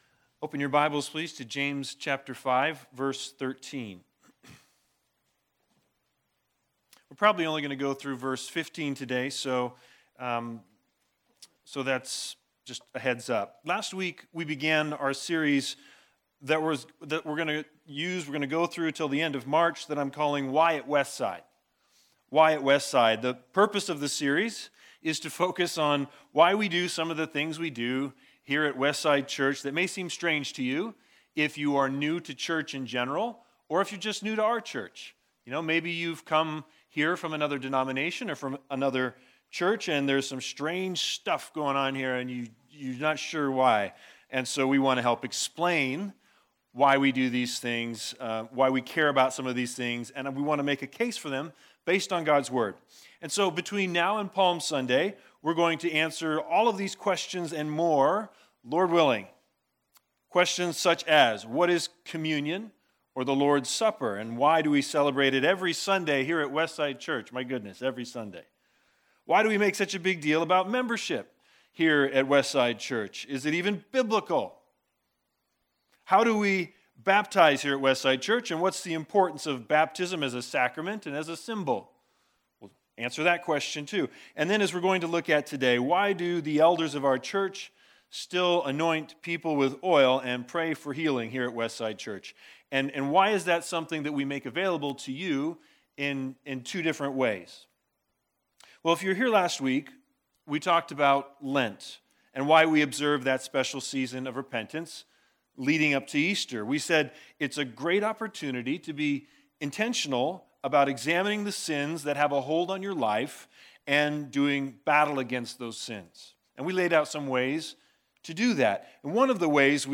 Passage: James 5:13-15 Service Type: Special Sermons The Big Idea: God calls us to Himself in all conditions. 3 conditions, & 3 commands for how Christians should respond: If anyone among you is suffering… let him pray.